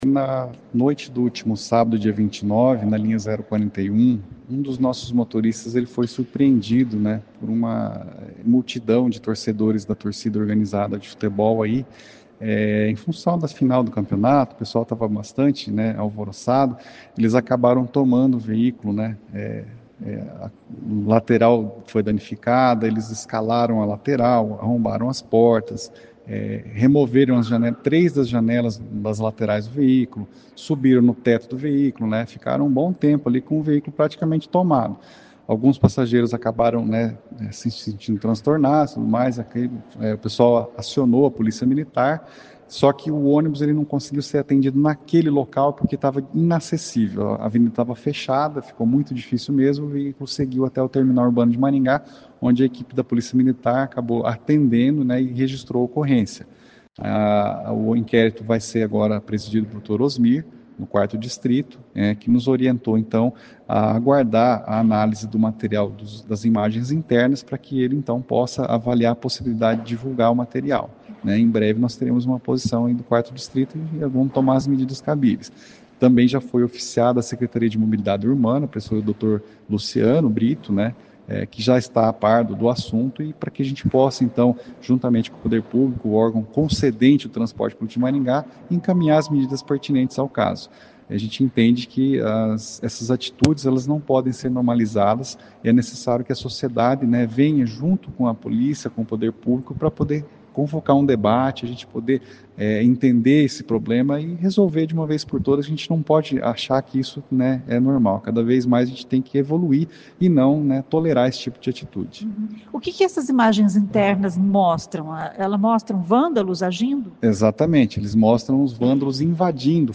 O advogado explica o que aconteceu exatamente.